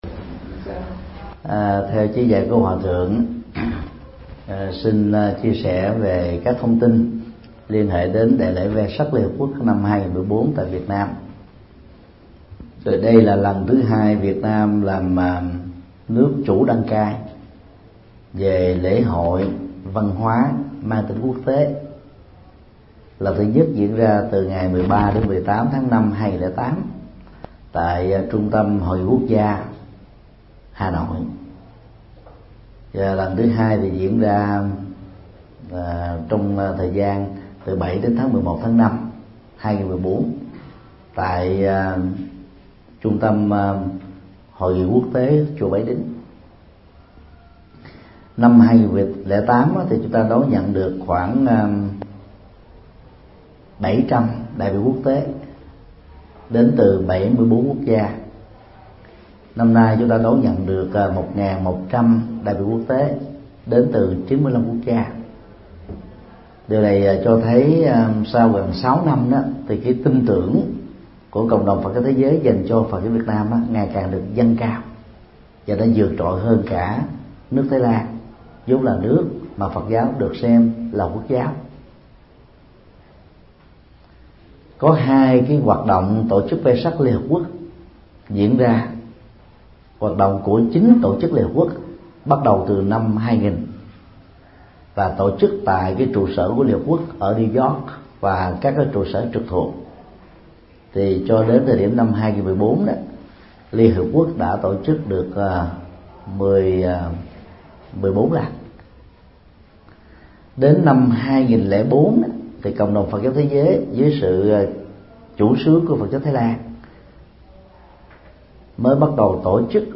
Vấn đáp: Vesak Liên Hiệp Quốc 2014 Việt Nam, Đạo Phật nguyên thủy